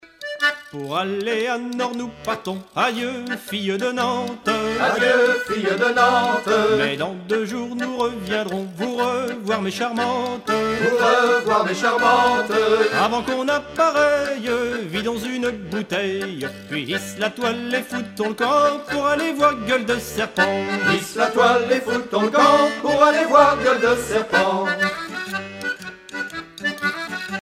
Genre strophique